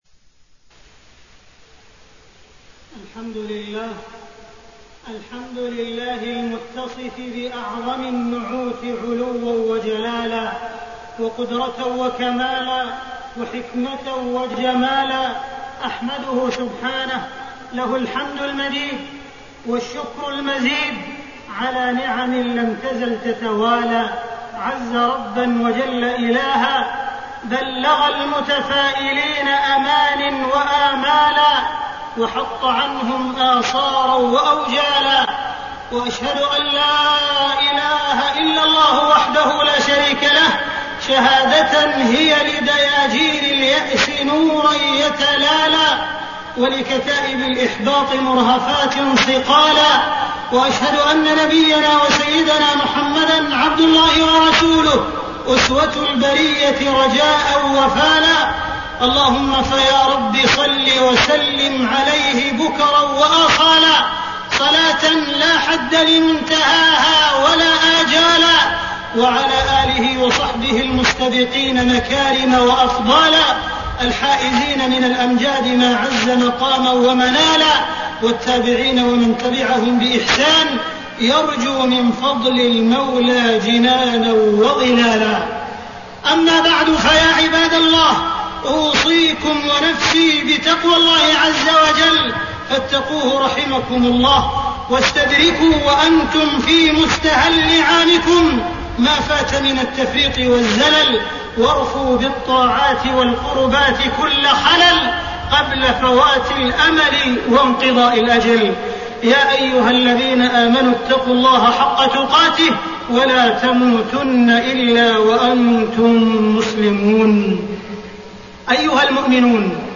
تاريخ النشر ٥ محرم ١٤٣٠ هـ المكان: المسجد الحرام الشيخ: معالي الشيخ أ.د. عبدالرحمن بن عبدالعزيز السديس معالي الشيخ أ.د. عبدالرحمن بن عبدالعزيز السديس التفاؤل ووقفة مع أحداث غزة